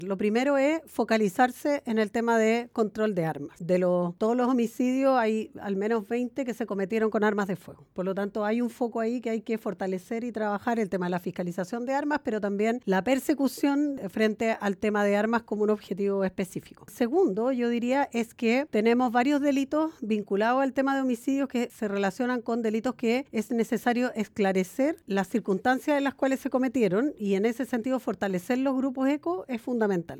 En entrevista con Radio Bío Bío en su visita a la zona, la subsecretaria de Prevención del Delito, Carolina Leitao, se refirió a las características de los últimos homicidios, indicando que se debe fortalecer la incautación y los equipos investigativos.